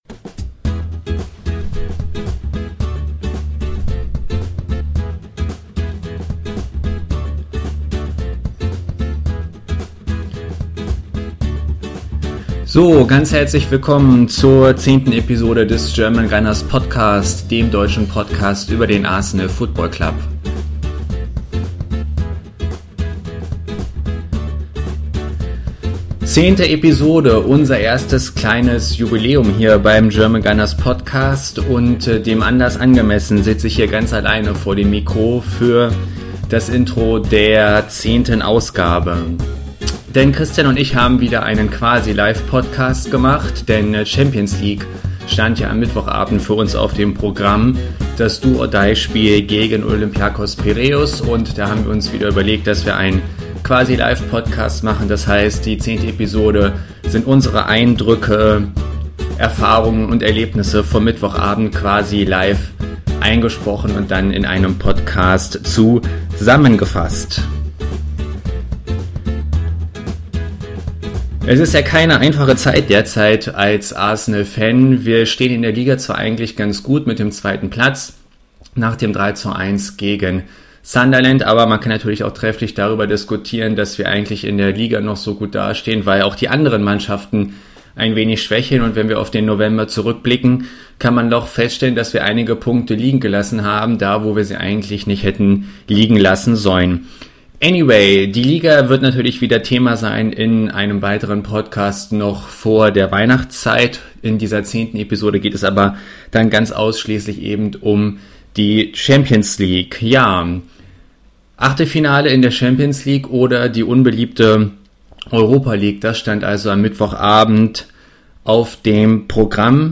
Wie angekündigt haben wir erneut einen Live Podcast eingespielt mit Einschätzungen vor dem Spiel, in der Halbzeit und nach dem Schlusspfiff.
episode_10_live_podcast.mp3